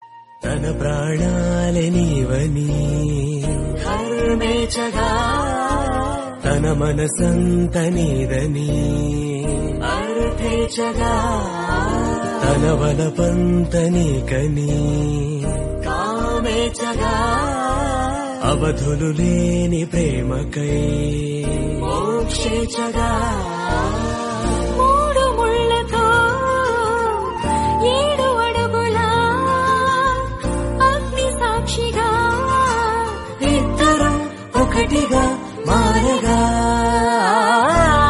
India